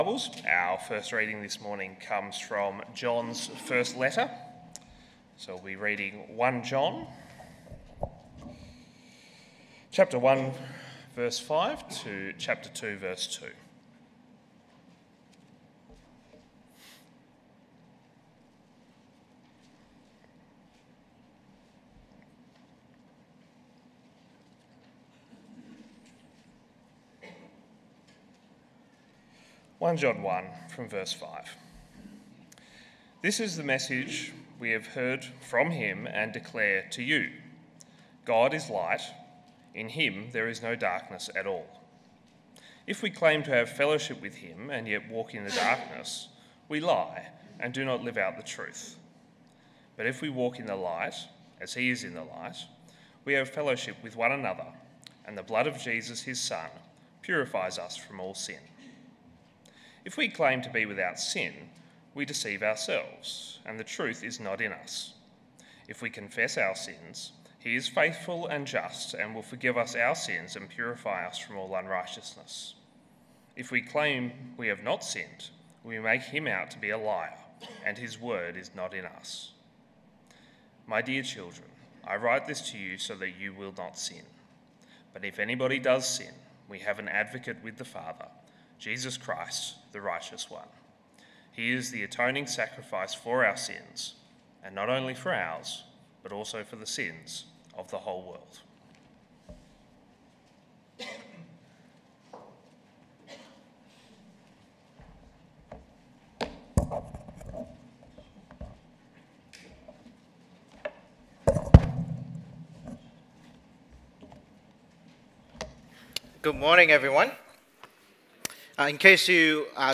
Sermons by Willetton Christian Church